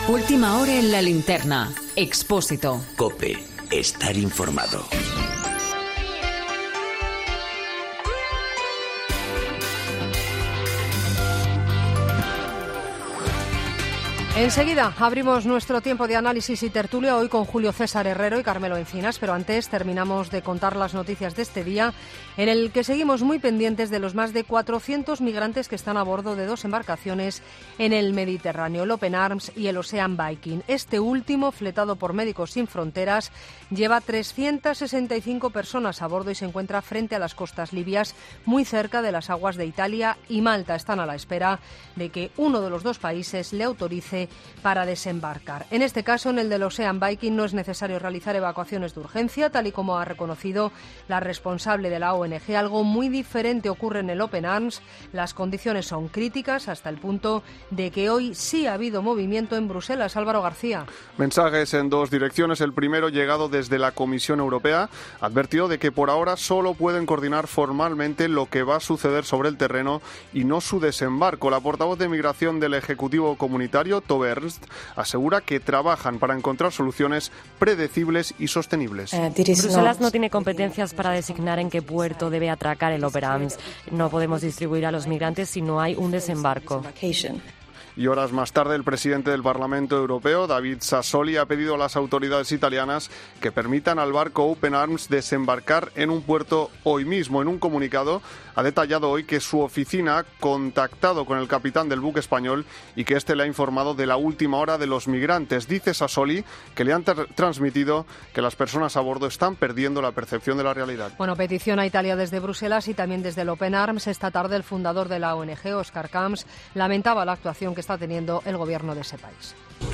Boletín de noticias de COPE del 16 de agosto de 2019 a las 22.00 horas